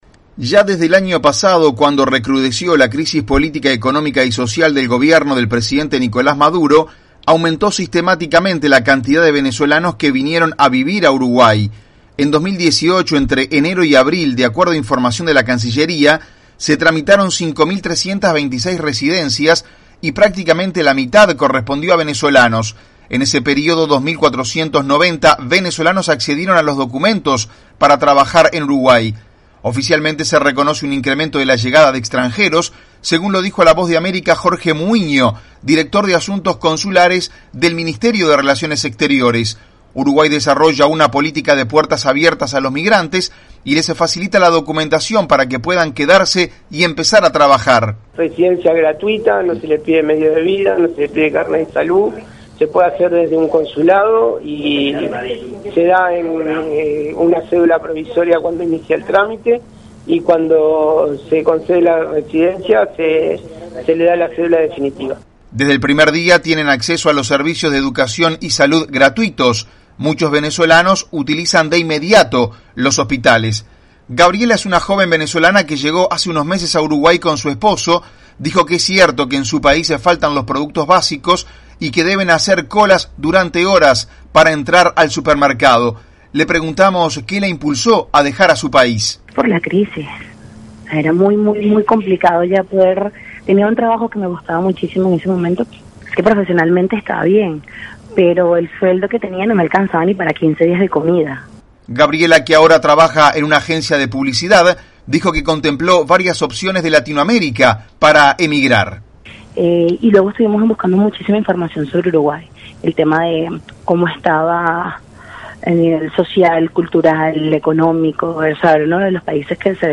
VOA: Informe de Uruguay